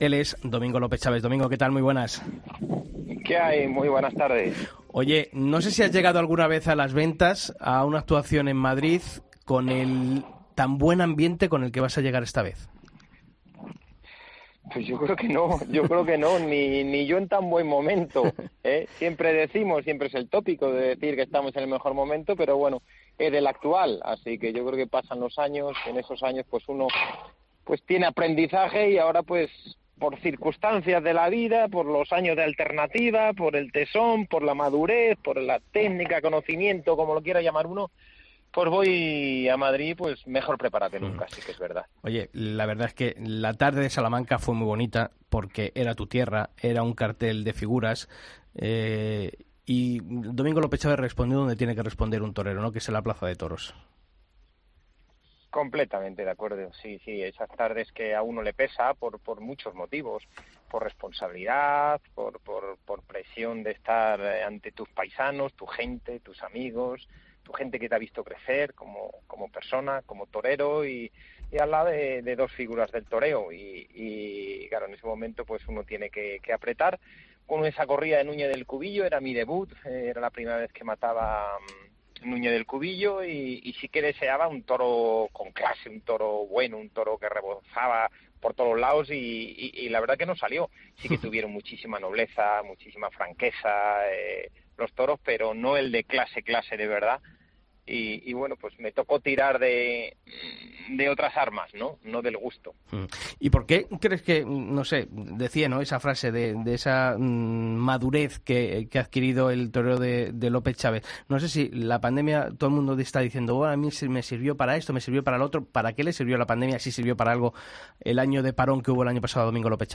Escucha la entrevista a Domingo López Chaves en El Albero